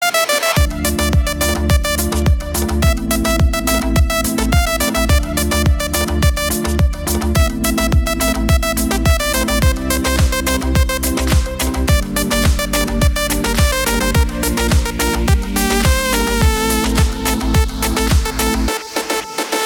• Качество: 320, Stereo
поп
веселые
без слов
красивая мелодия